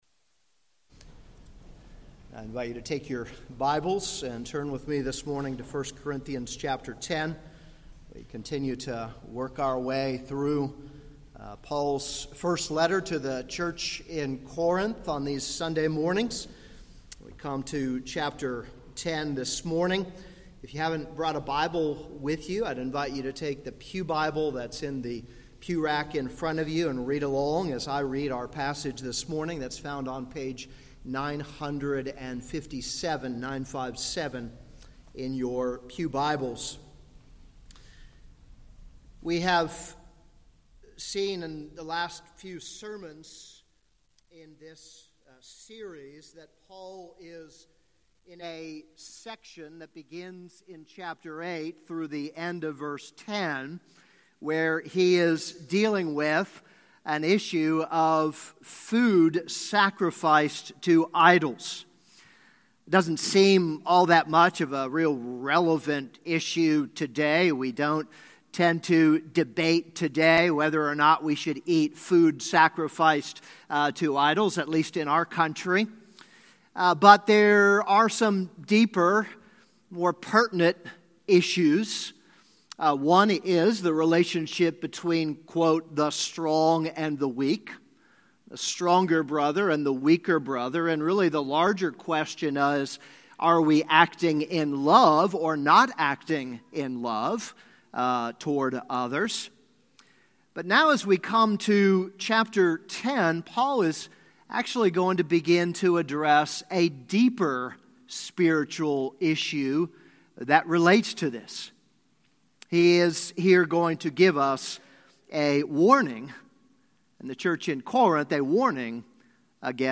This is a sermon on 1 Corinthians 10:1-13.